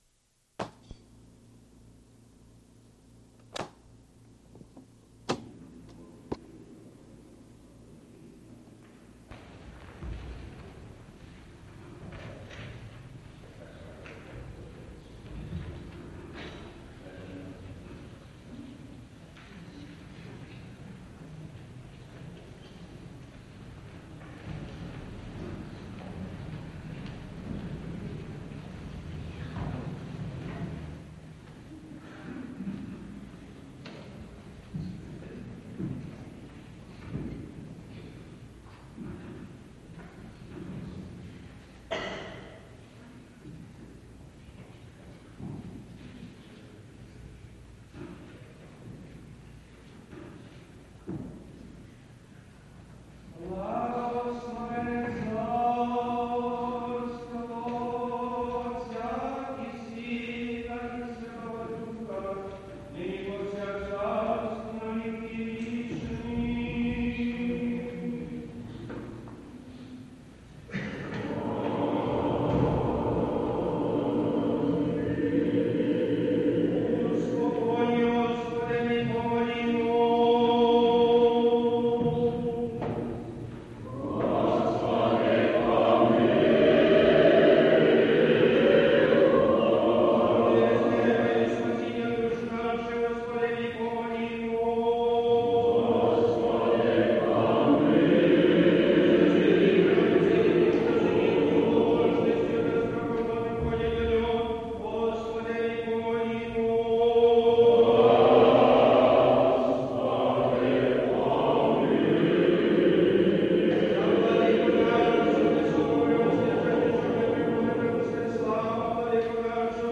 Hierarchal Divine Liturgy at St. Mary the Protectress Ukrainian Orthodox Cathedral
Gospel reading
Cherubic Hymn
Sermon by Metropolitan Mstyslav